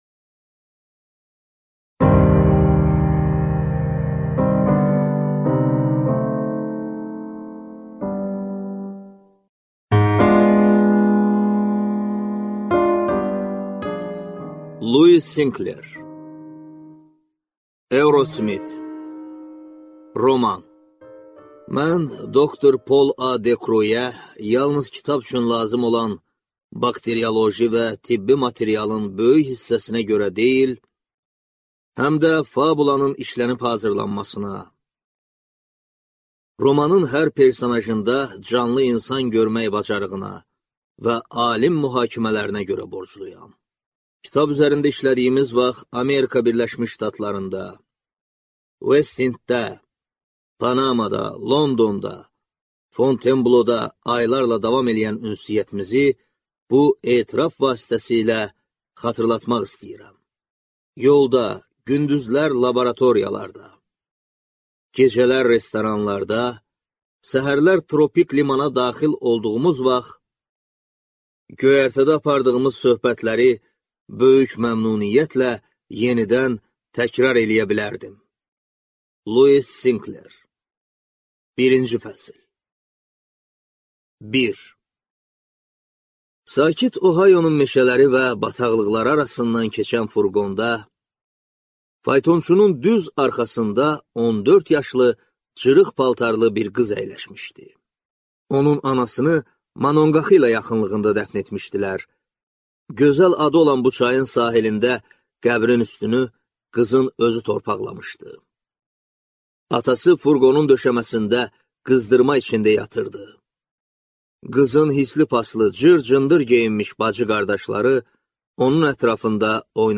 Аудиокнига Eurosmit | Библиотека аудиокниг